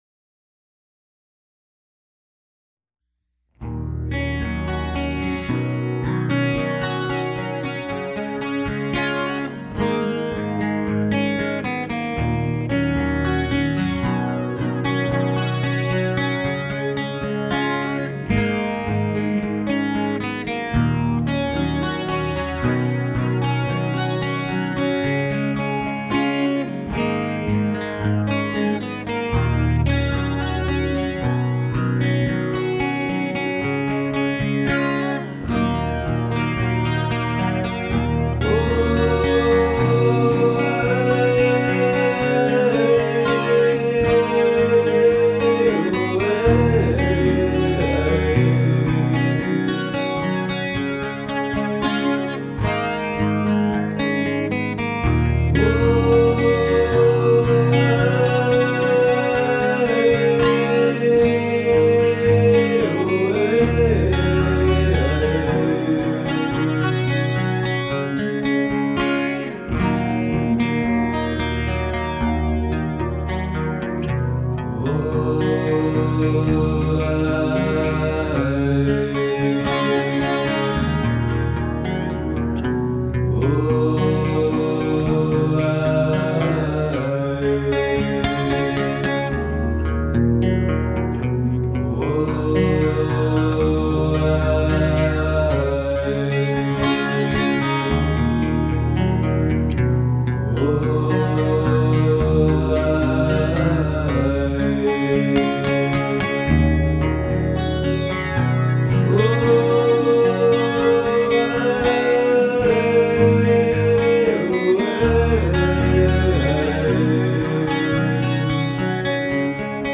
Rock & Roll
Rock/Hard-rock